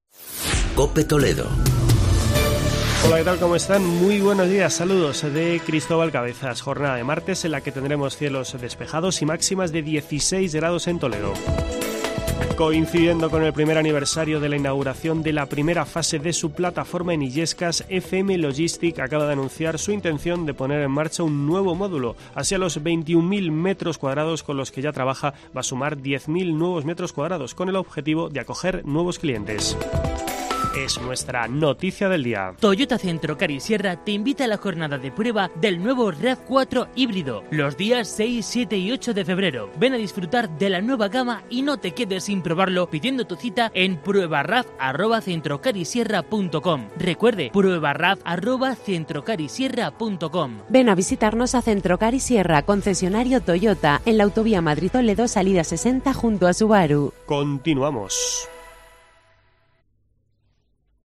La ampliación de la plataforma de FM Logistic en Illescas es el asunto que te contamos en este boletín informativo de la Cadena COPE en la provincia.